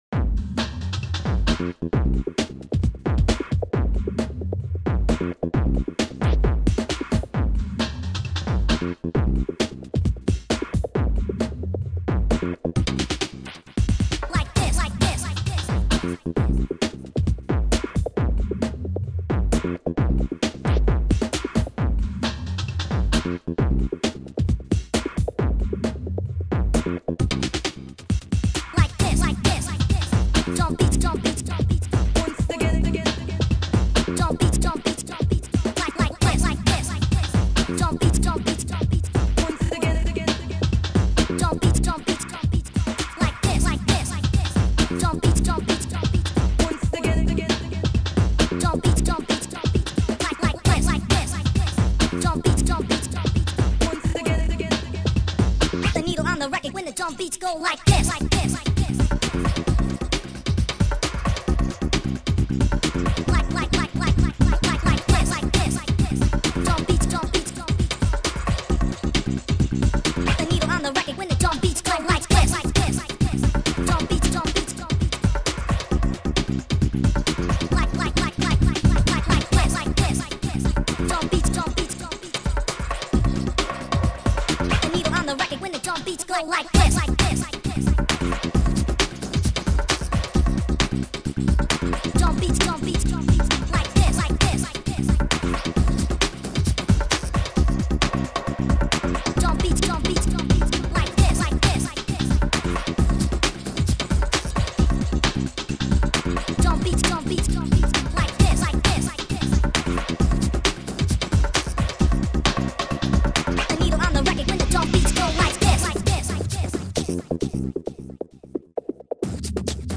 .mp3] (Breakbeat)